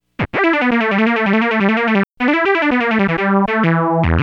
Synth 21.wav